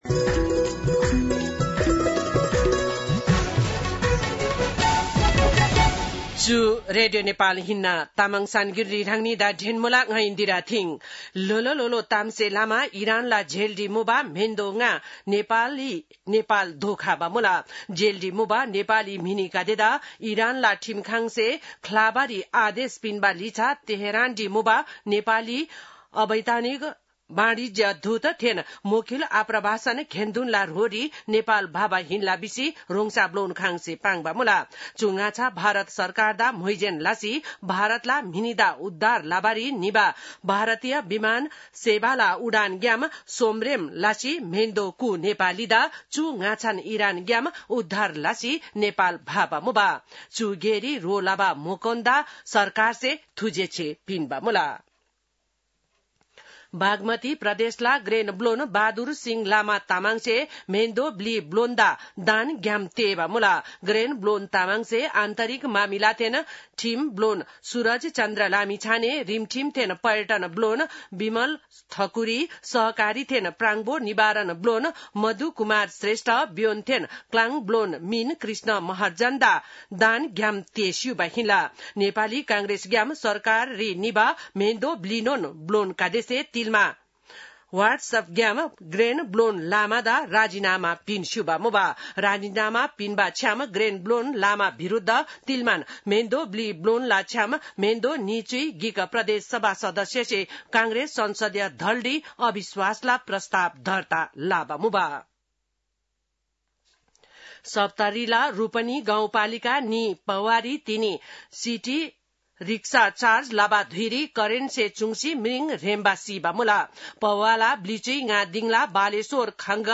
तामाङ भाषाको समाचार : १० साउन , २०८२